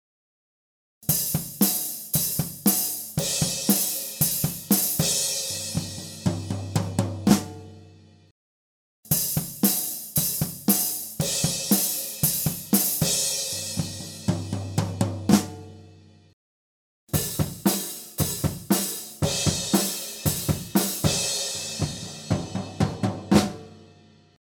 Διερεύνηση στερεοφωνικών τεχνικών ηχογράφησης και μίξης κρουστού οργάνου (drums) μέσω διαφορετικών στερεοφωνικών τεχνικών μικροφώνων.